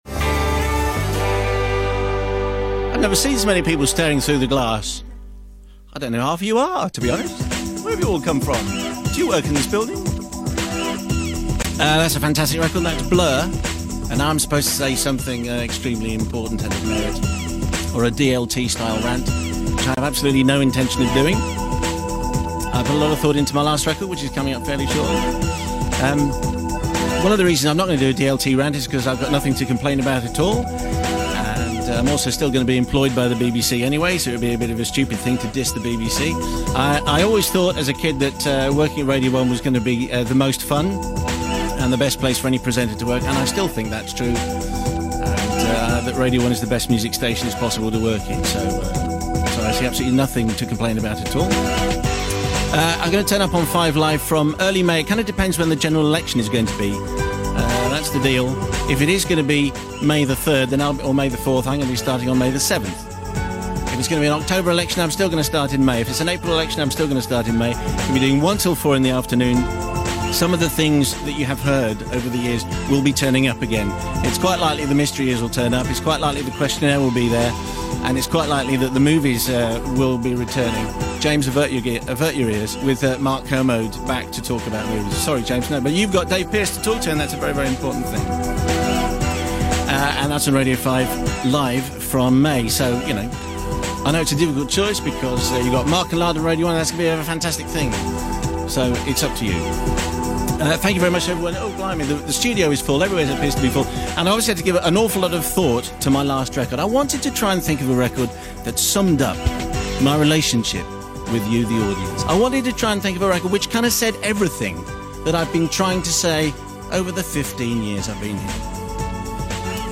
Simon Mayo - end of last Radio 1 show - 2001